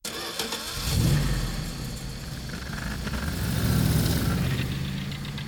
carstarting.wav